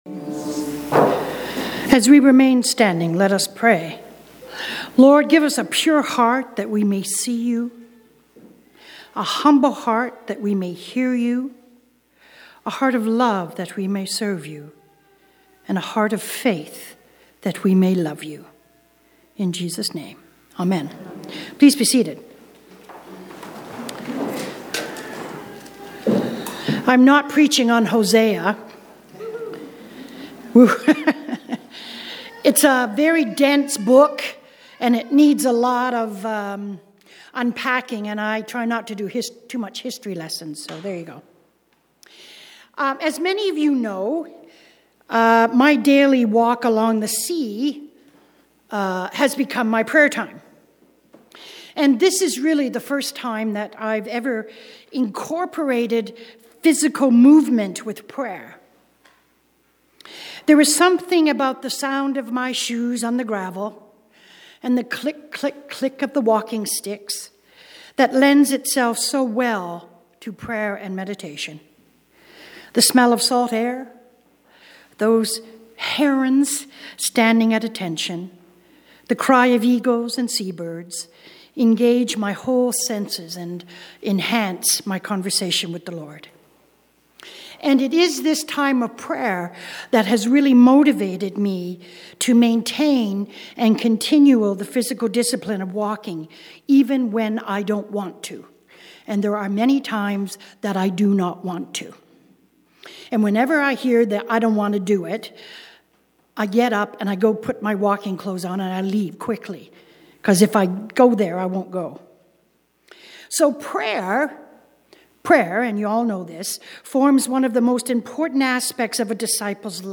Sermons | St. David and St. Paul Anglican Church